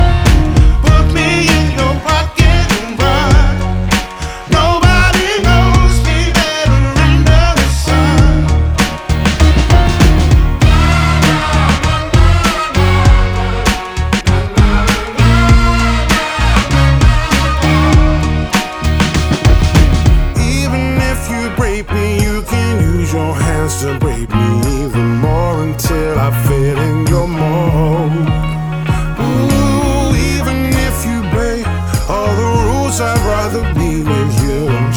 Жанр: Поп / R&b / Соул